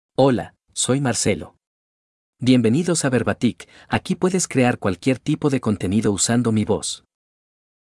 MaleSpanish (Bolivia)
Marcelo — Male Spanish AI voice
Marcelo is a male AI voice for Spanish (Bolivia).
Voice sample
Marcelo delivers clear pronunciation with authentic Bolivia Spanish intonation, making your content sound professionally produced.